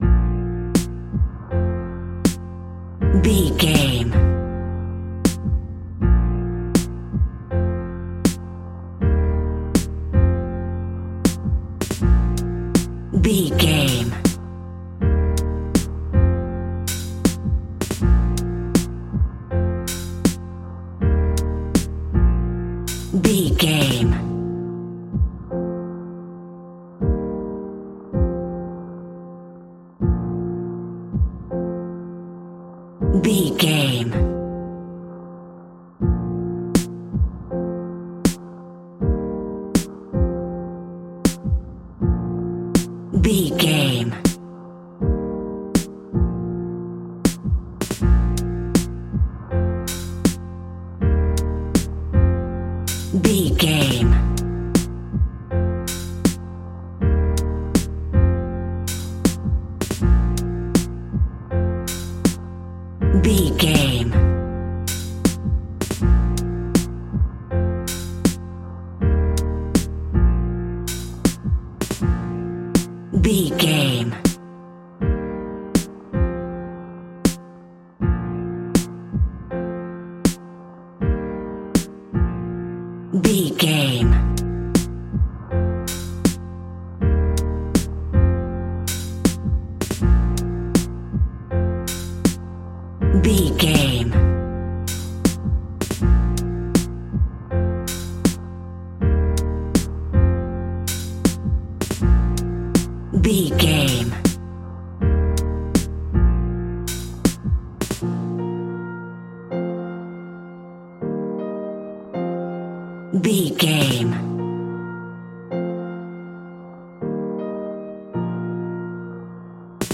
Happy Hip Hop.
Ionian/Major
B♭
chilled
laid back
hip hop drums
hip hop synths
piano
hip hop pads